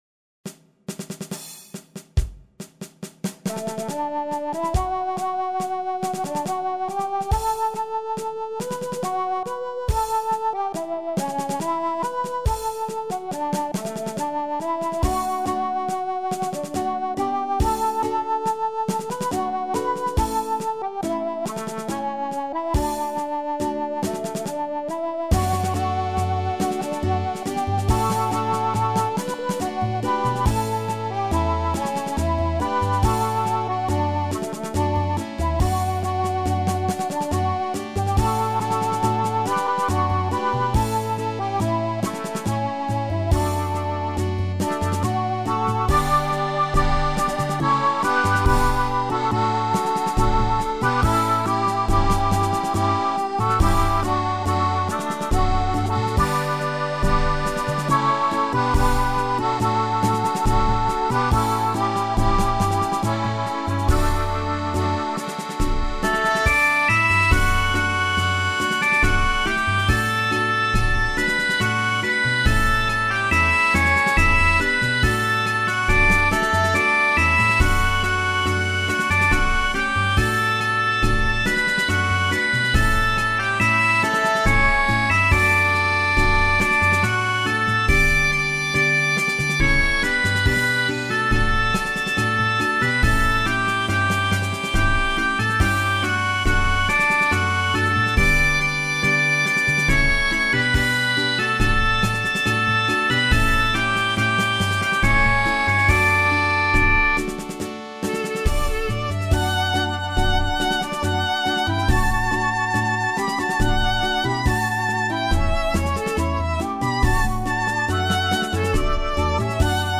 Celtic
Scottish Folk